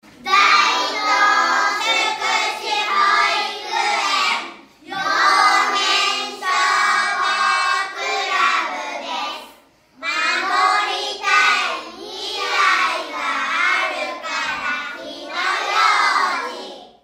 「火災予防運動」や「歳末特別警戒」の一環で、管内の子ども達（幼年消防クラブ員）が録音した音声を消防車から流し、パトロールをします。
子ども達は、かわいい元気いっぱいの声で「守りたい 未来があるから 火の用心」と音声を録音して協力して頂きました。